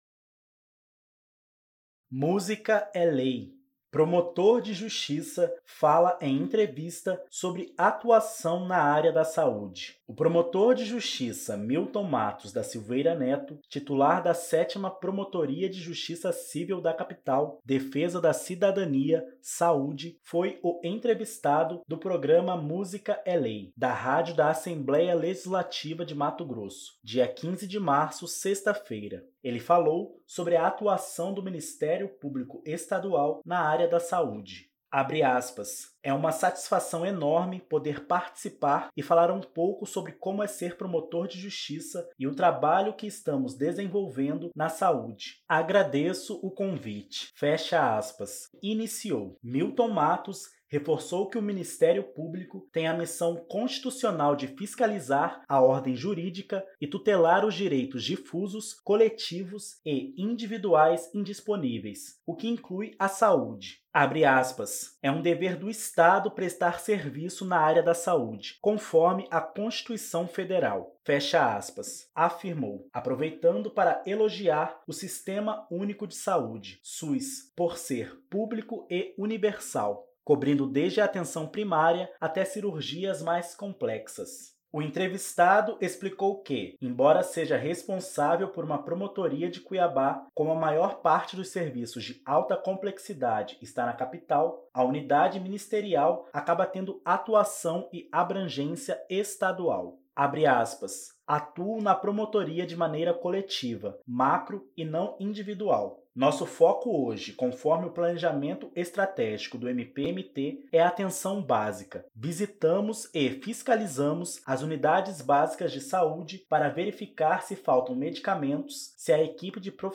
Promotor de Justiça fala em entrevista sobre atuação na área da Saúde
O promotor de Justiça Milton Mattos da Silveira Neto, titular da 7ª Promotoria de Justiça Cível da Capital - Defesa da Cidadania (Saúde), foi o entrevistado do programa Música é Lei, da rádio da Assembleia Legislativa de Mato Grosso, dia 15 de março (sexta-feira). Ele falou sobre a atuação do Ministério Público Estadual na área da Saúde.
Promotor de Justiça fala em entrevista sobre atuação na área da Saúde.mp3